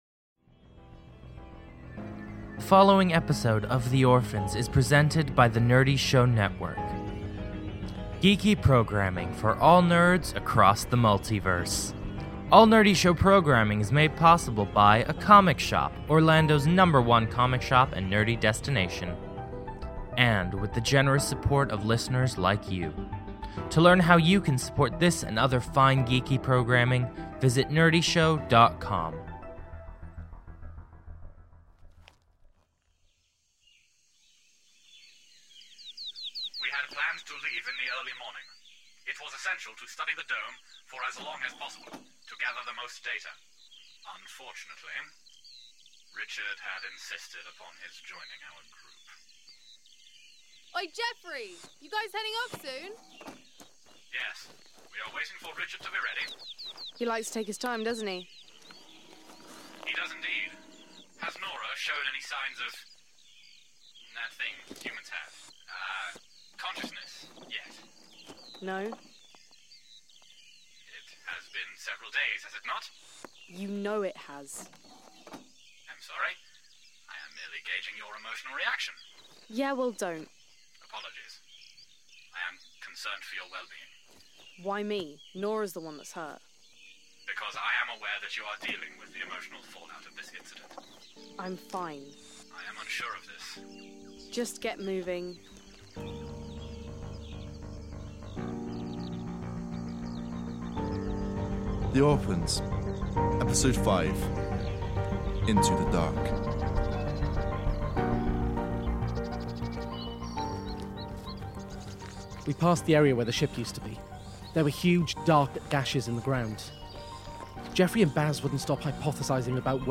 The Orphans is a cinematic audio drama chronicling the castaways of downed starship, The Venture - Stranded on a hostile planet, struggling to remember how they arrived and who they are.